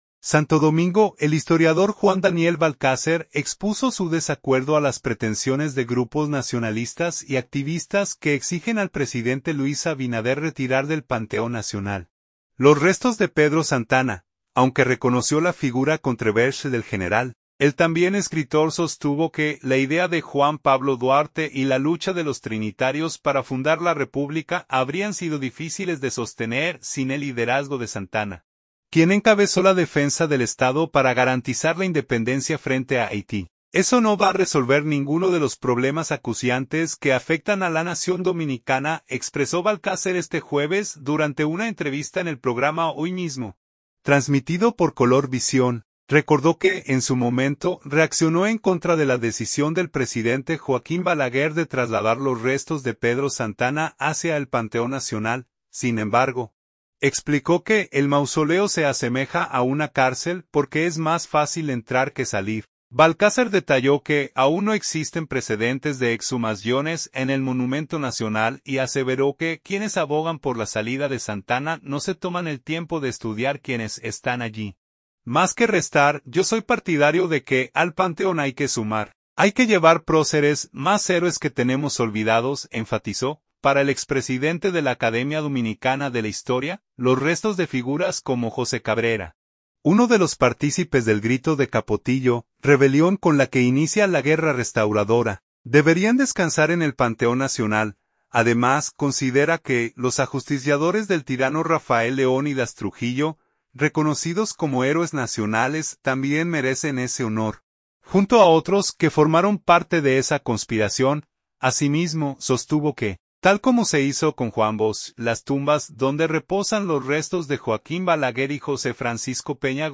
durante una entrevista en el programa Hoy Mismo, transmitido por Color Visión.